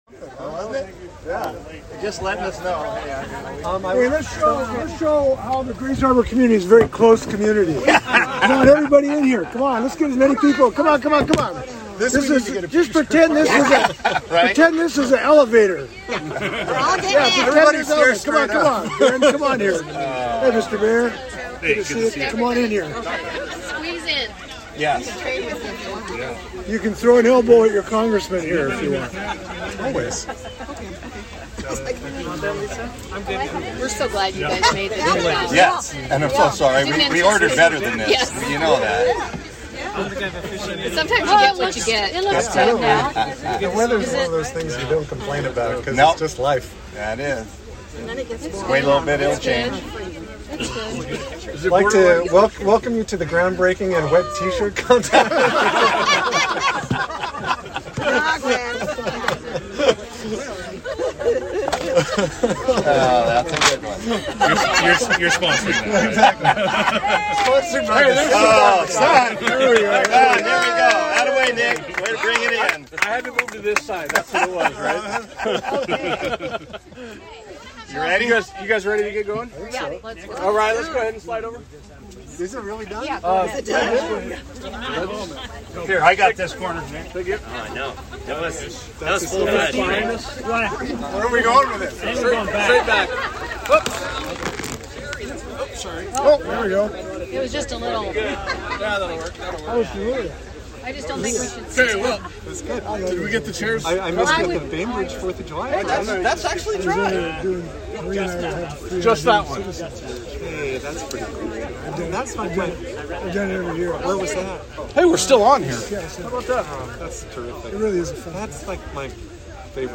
ABERDEEN, WA — Sen. Maria Cantwell (D-WA) attended the groundbreaking of the Aberdeen-Hoquiam Flood Protection Project yesterday and celebrated the major step forward to protect homes and businesses in Grays Harbor County from severe flooding, spurring future opportunities for economic growth.